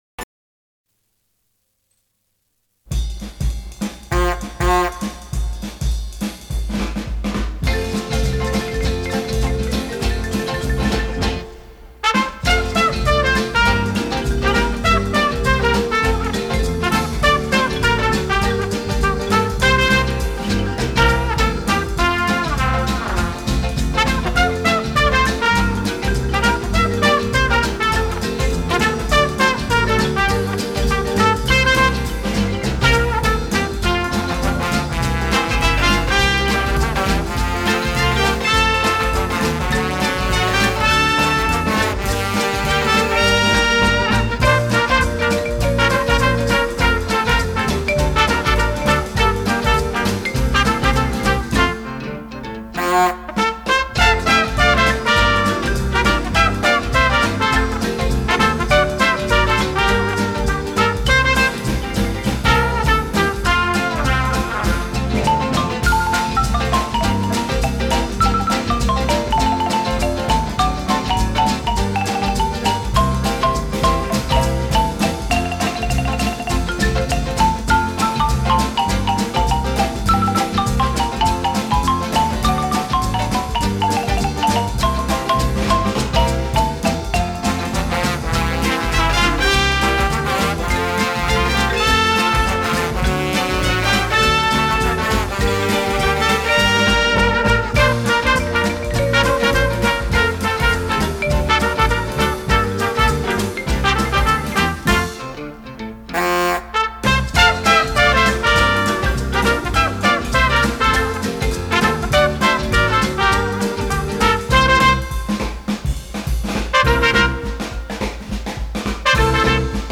Eguerdiko Ahotsa magazine, informatibo, giza-kultural bat da, eta bertan prentsa errepasoa, elkarrizketak, kaleko iritziak, kolaboratzaileen kontakizunak, musika, agenda eta abar topatu ditzazkezu.